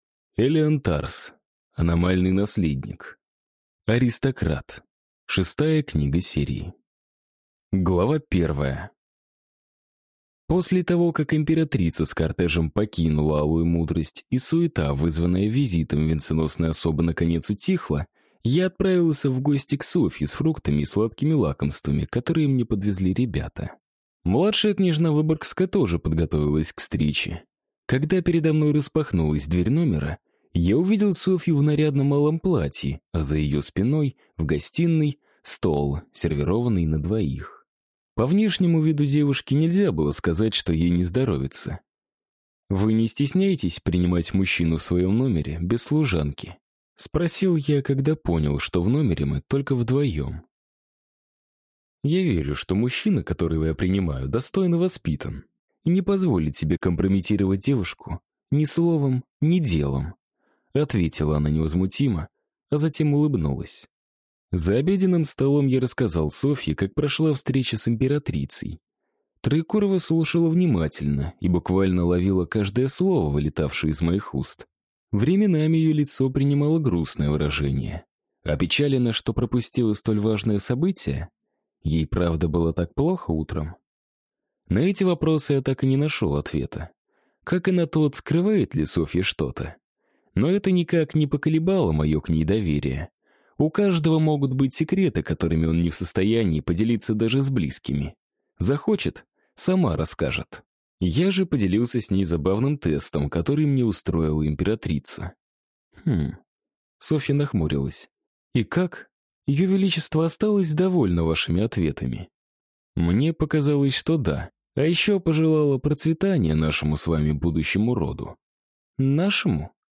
Аудиокнига Аномальный Наследник. Аристократ | Библиотека аудиокниг